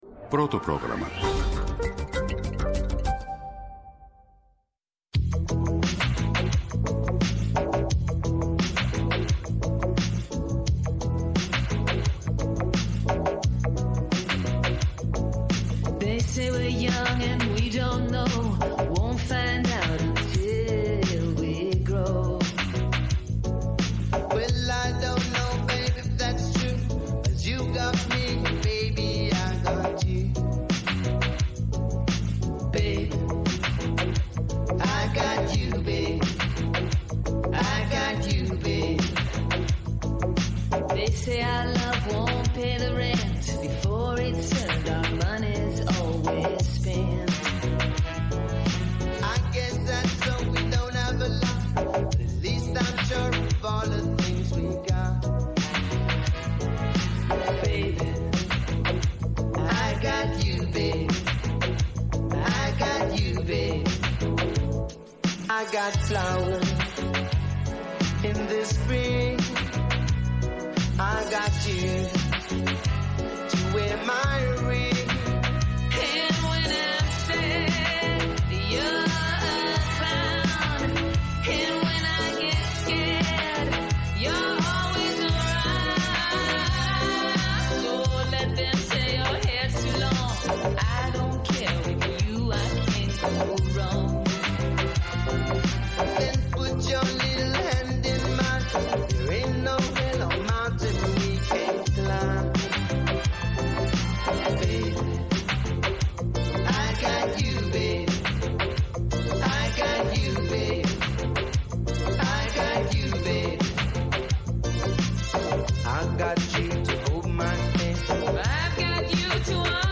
αστυνομικός συντάκτης
Ο Νίκος Παπαϊωάννου, Υφυπουργός Παιδείας, αρμόδιος για την Τριτοβάθμια Εκπαίδευση
στο Πρώτο Πρόγραμμα της Ελληνικής Ραδιοφωνίας